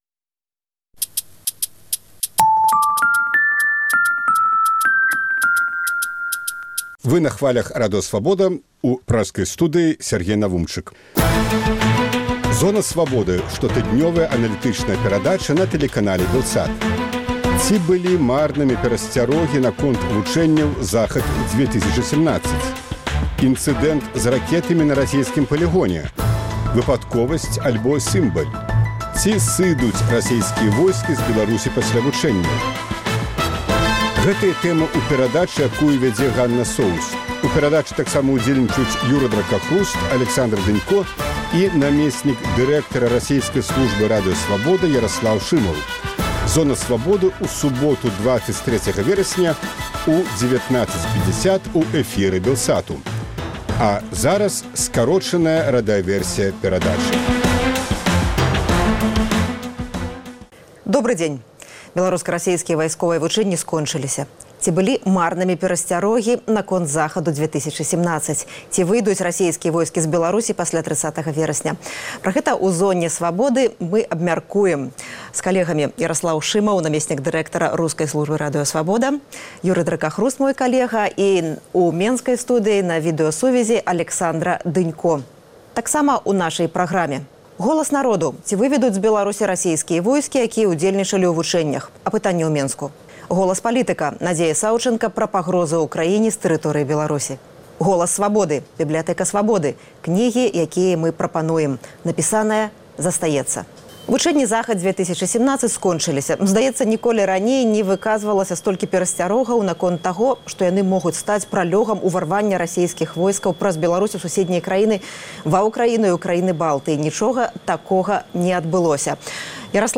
А зараз – скарочаная радыёвэрсія перадачы.